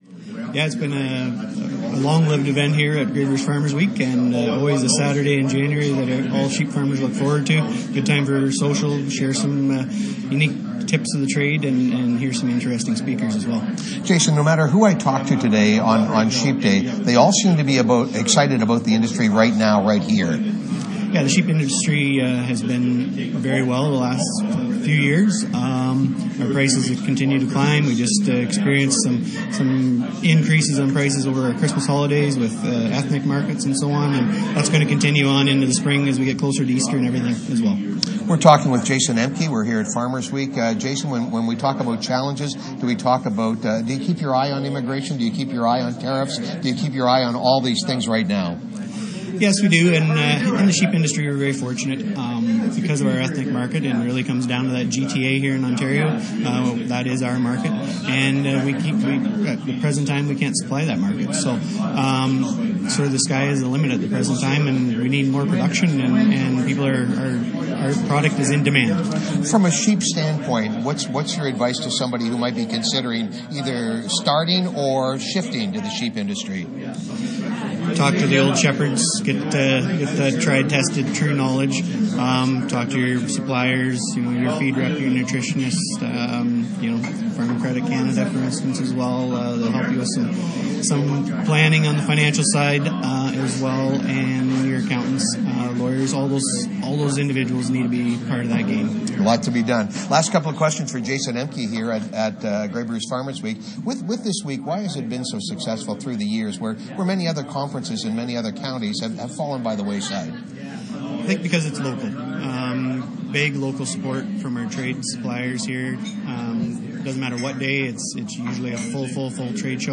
The 59th annual Grey Bruce Farmers Week continues at the Elmwood Community Centre.